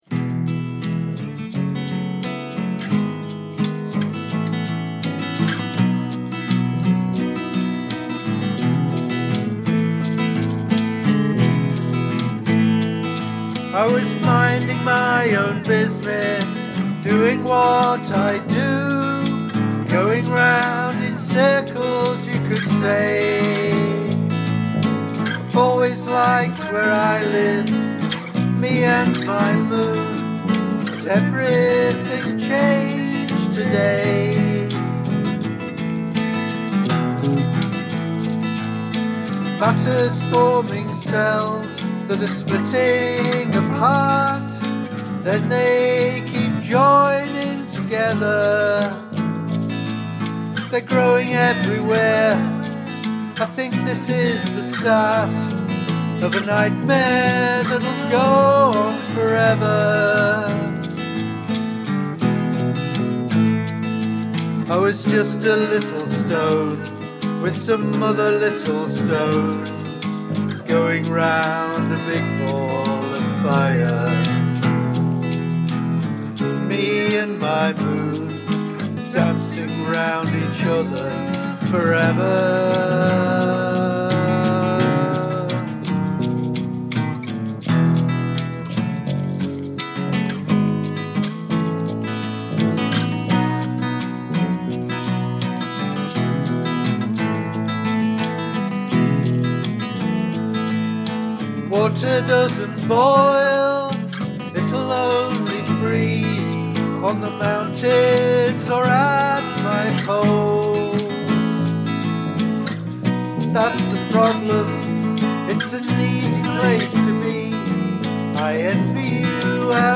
This is so serene.
Beautiful chords too.
NIce progression and meandering flow to the music.